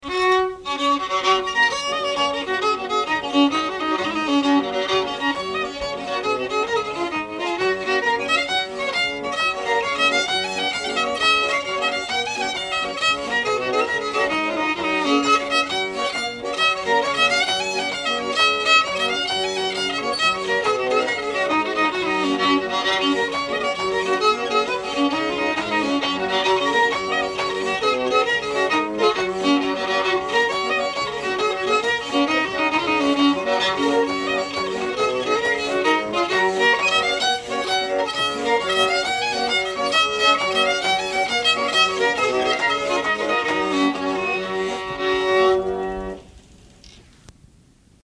pump organ